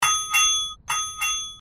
envirobell.mp3